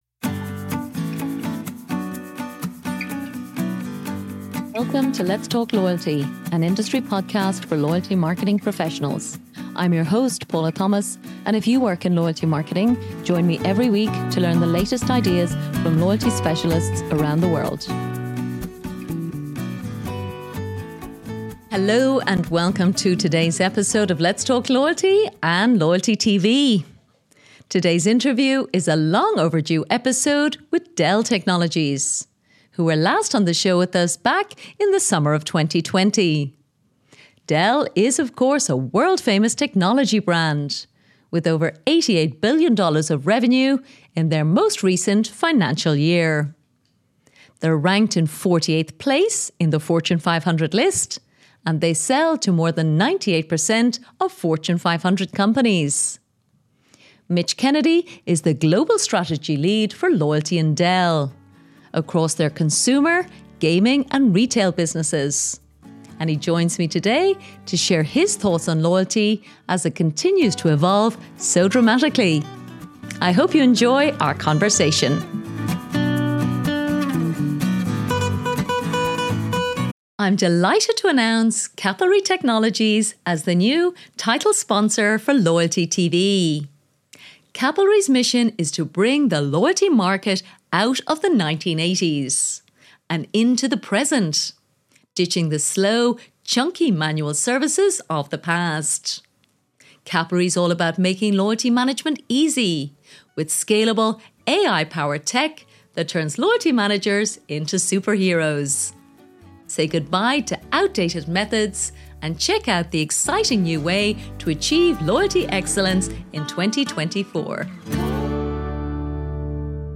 Today’s interview is a long overdue episode with Dell Technologies which was last on the show back in the summer of 2020.